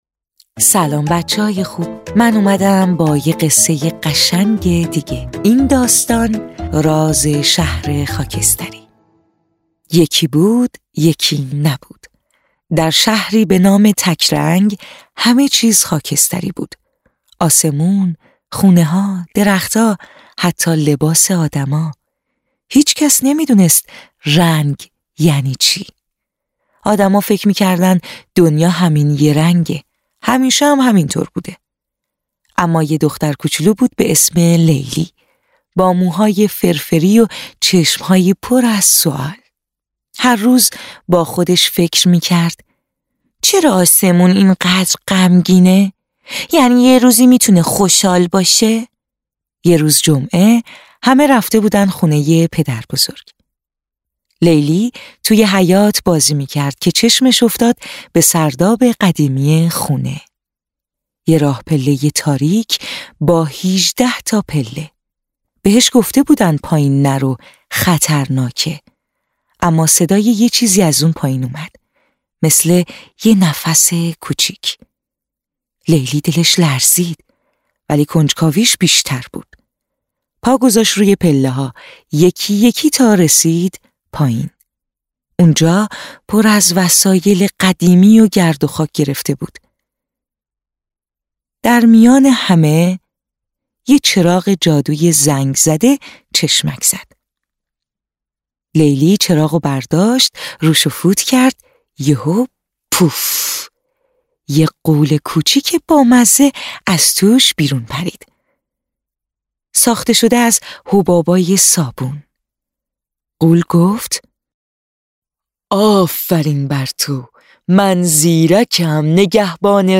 قصه‌های کودکانه صوتی – این داستان: راز شهر خاکستری
روایتی نرم و تصویری که شنیدن آن، تخیل کودک را بیدار می‌کند و او را همراه خود می‌برد.
تهیه شده در استودیو نت به نت